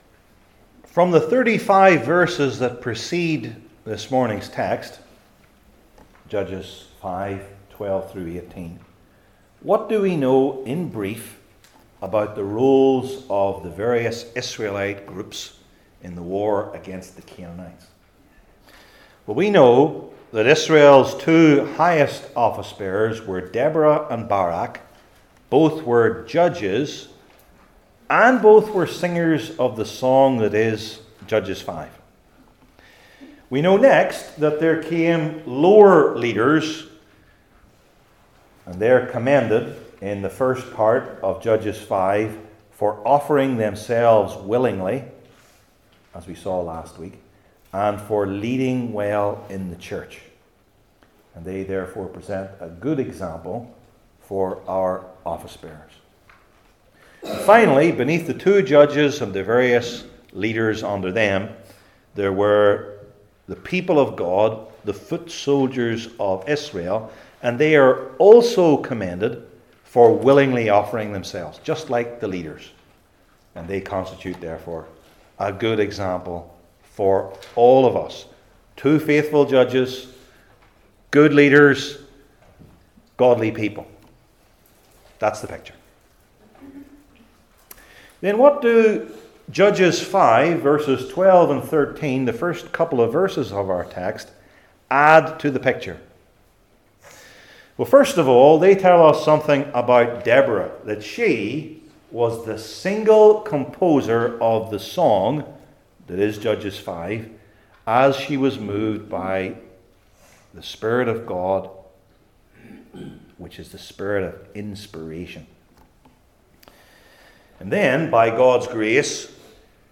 Old Testament Sermon Series